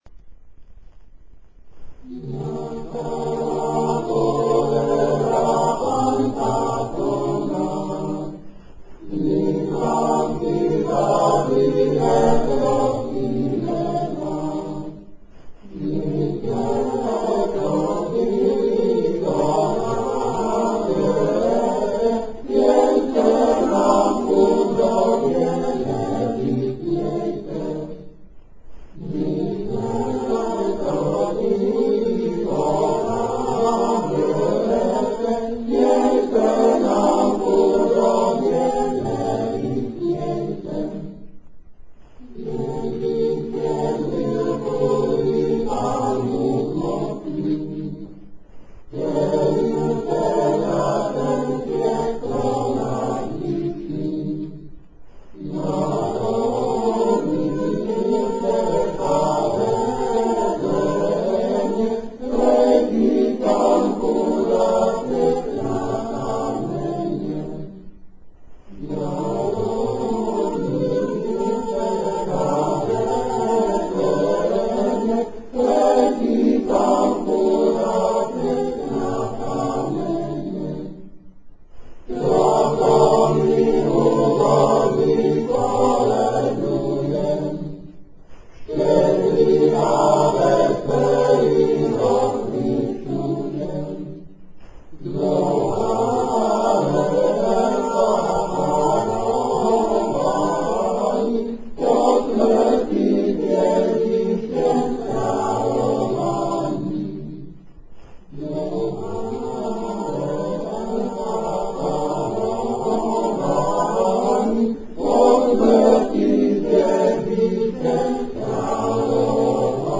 18. komorní koncert na radnici v Modřicích
Vybrané třebíčské koledy (texty) - amatérské nahrávky ukázek: